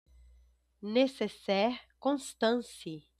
Observação atual Audio precisa mais fluido e ágil, mas com bom entendimento.